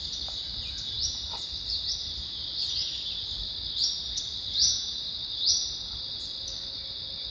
Yellow-olive Flatbill (Tolmomyias sulphurescens)
Location or protected area: Santa Ana
Condition: Wild
Certainty: Observed, Recorded vocal